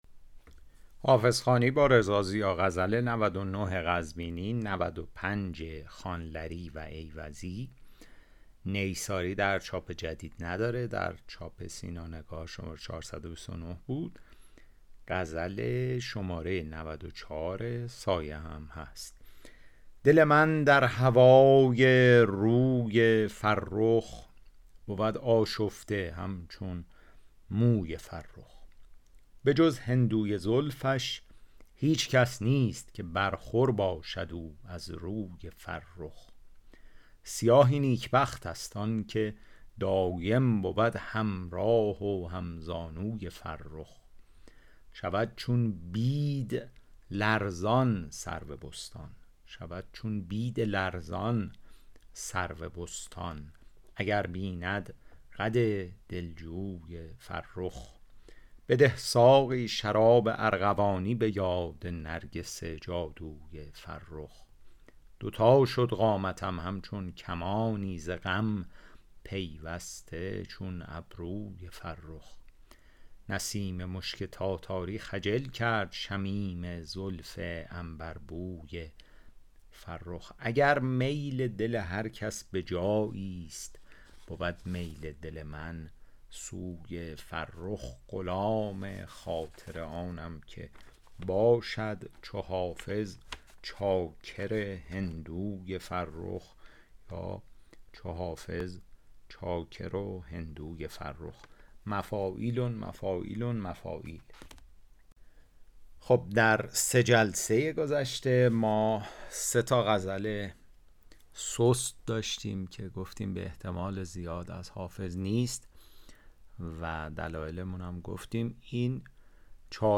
شرح صوتی غزل شمارهٔ ۹۹